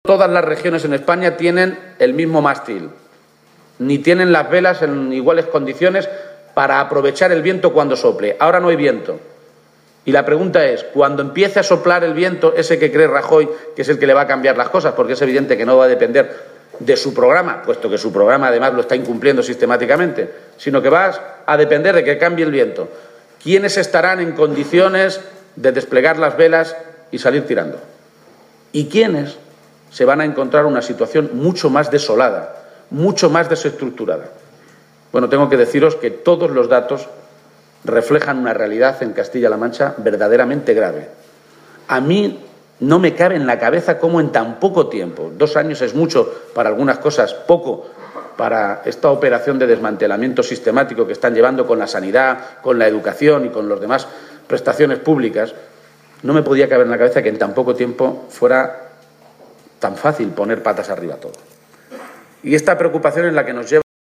García-Page ha hecho estas consideraciones durante su intervención en el acto inaugural del VI Congreso Regional del sindicato UGT celebrado en Toledo, donde el líder de los socialistas castellano-manchegos ha afirmado que todos los indicadores sobre la situación actual de la Región «reflejan una realidad verdaderamente grave en Castilla-La Mancha», tras la política de recortes de los gobiernos de Cospedal y Rajoy.